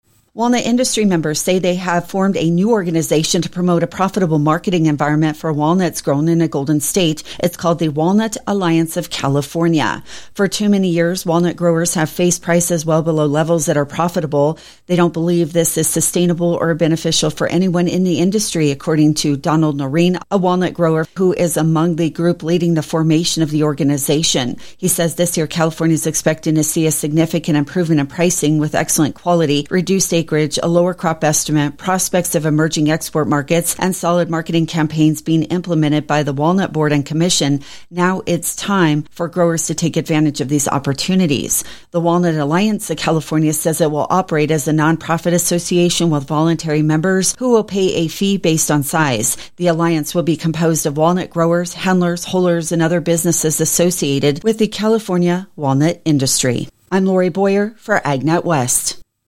Synopsis: This audio report talks about a new group formed to help get walnut prices off the floor. Among its members are growers, handlers and processors.